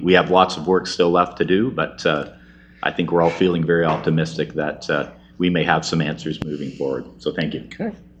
Hillier Coun. Chris Braney, who is a member of the County Road 49 Working Group, said that there is still lots of work to be done, but the future is hopeful: